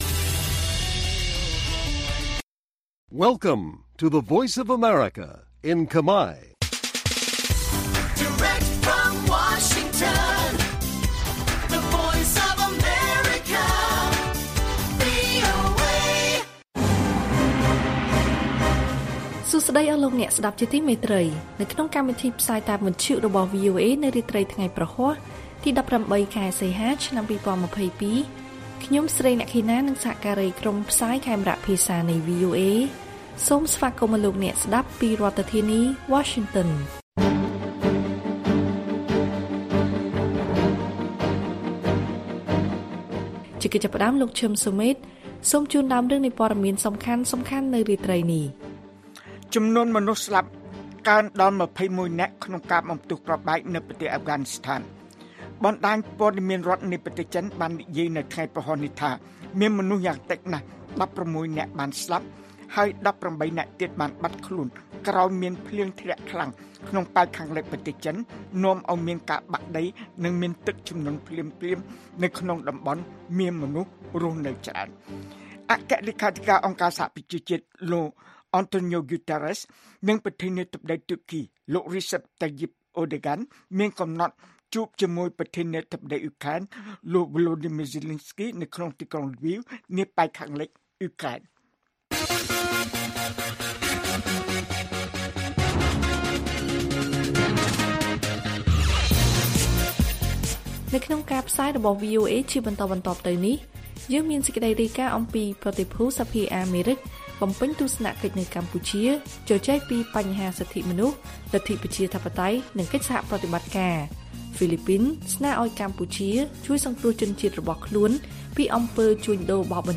ព័ត៌មានពេលរាត្រី ១៨ សីហា៖ ប្រតិភូសភាអាមេរិកបំពេញទស្សនកិច្ចនៅកម្ពុជាជជែកពីបញ្ហាសិទ្ធិមនុស្ស ប្រជាធិបតេយ្យនិងសហប្រតិបត្តិការ